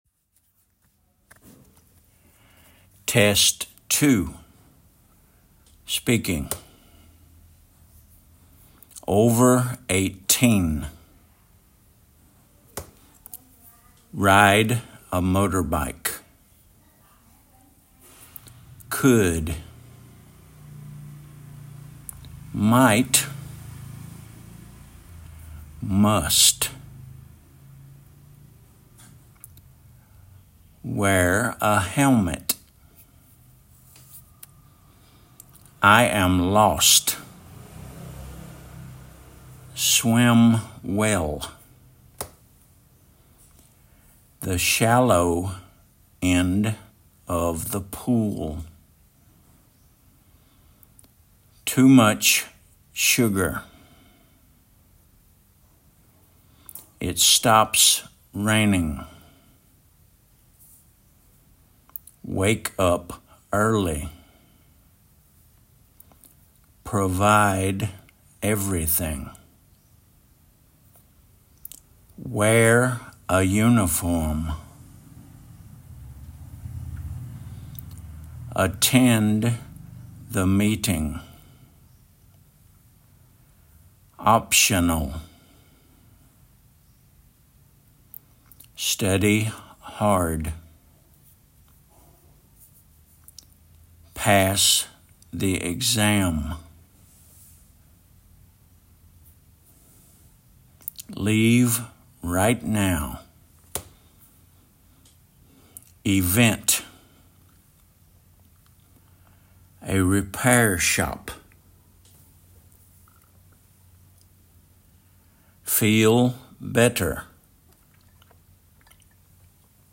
over eighteen /ˈəʊvə eɪˈtiːn/
ride a motorbike /raɪd ə ˈməʊtəbaɪk/
wear a helmet /wɛə(r) ə ˈhɛlmɪt/
carbon emissions /ˈkɑːbən ɪˈmɪʃənz/